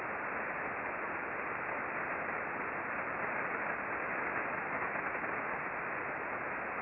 We observed mostly S-bursts throughout the pass.
The red trace is interference, which is apparent from the audio.